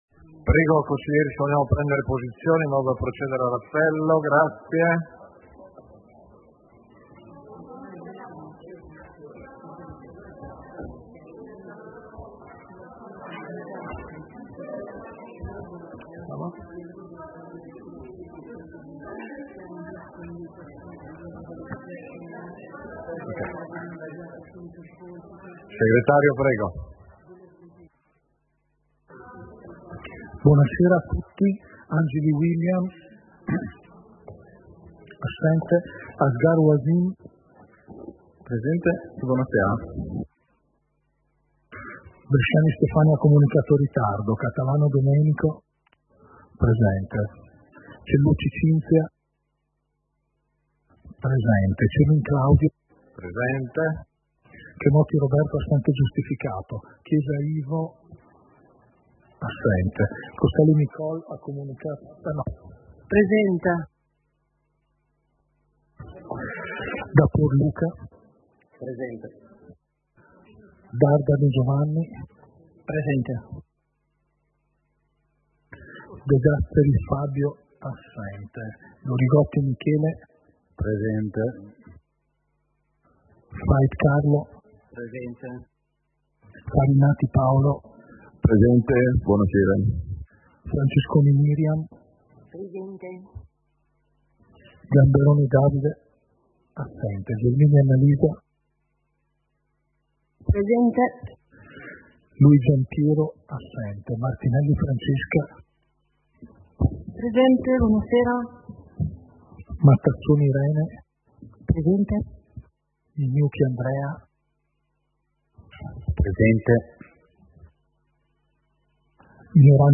Seduta del consiglio comunale - 10.12.2025